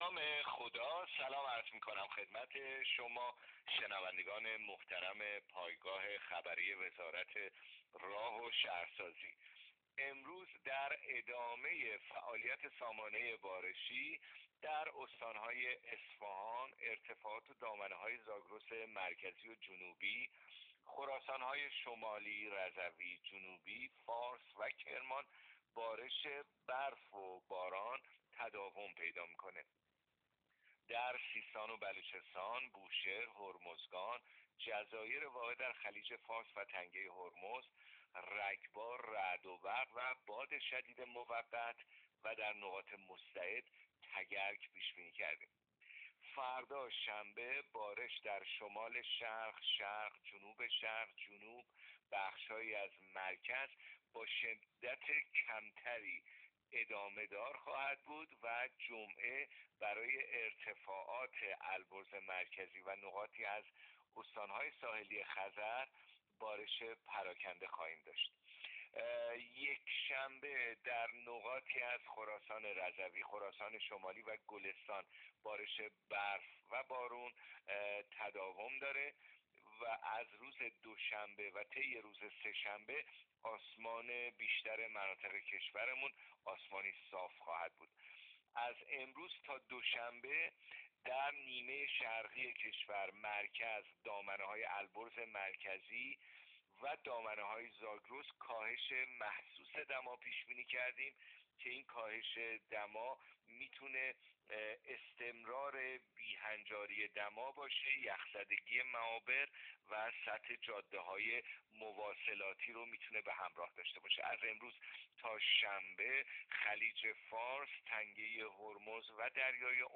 گزارش رادیو اینترنتی پایگاه‌ خبری از آخرین وضعیت آب‌وهوای ۲۸ آذر؛